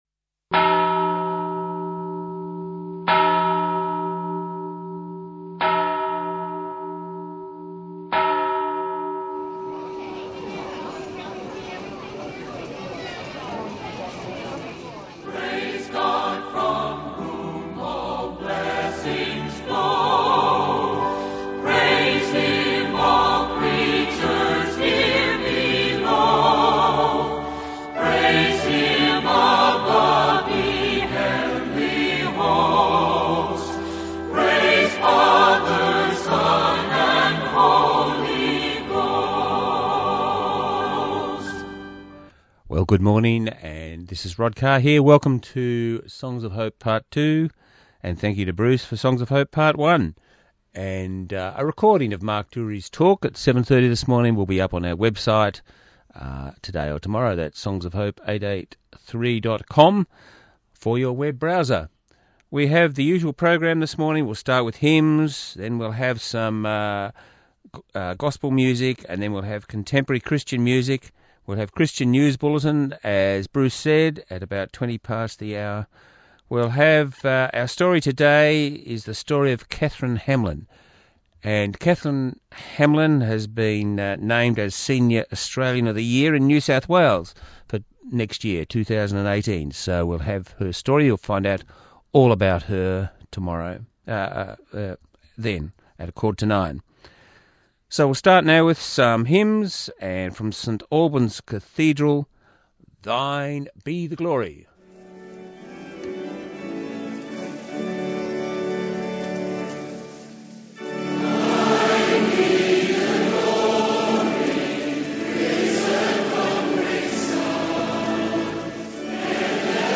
19Nov17 1hr Christian music
Listen again to Songs of Hope part 2 broadcast on 19Nov17 on Southern FM 88.3